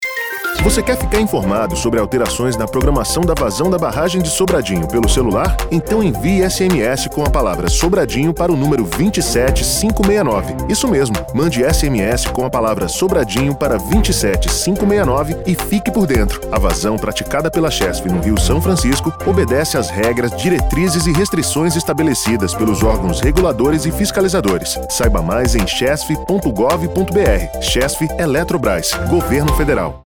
SMS_Sobradinho_Spot.mp3